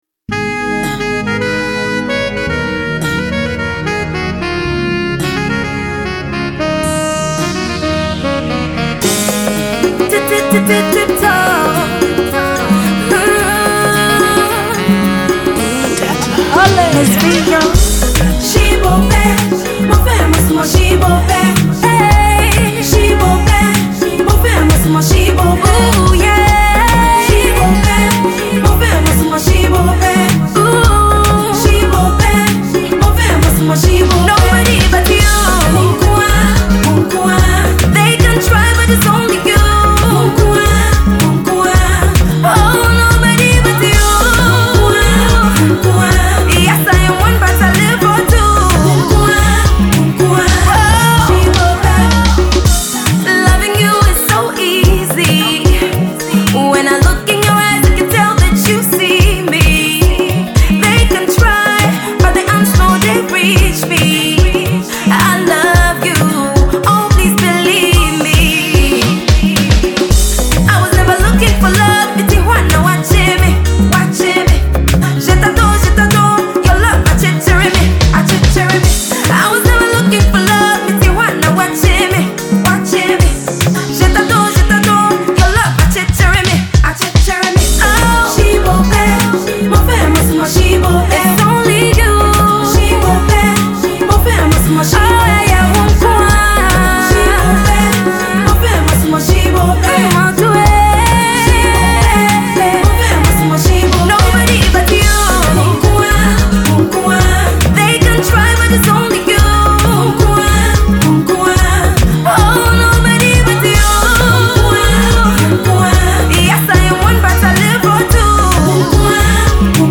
powerful love song